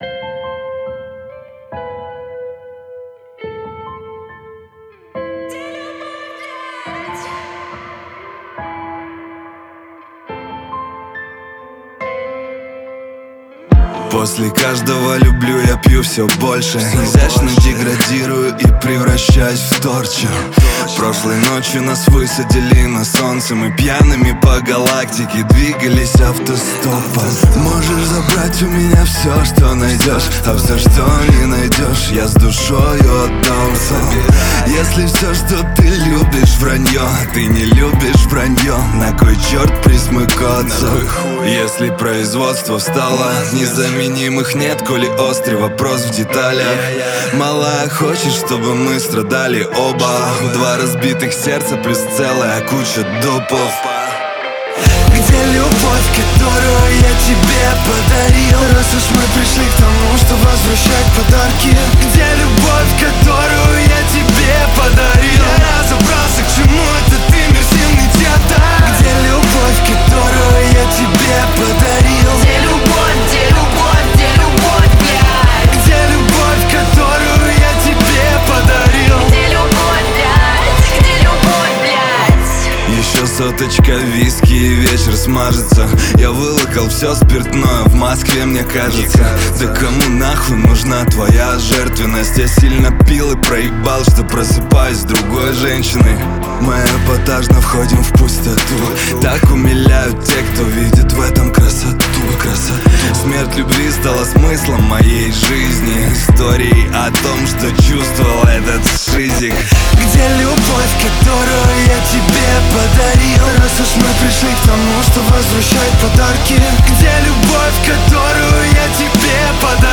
Категории: Русские песни, Рэп и хип-хоп, Рок.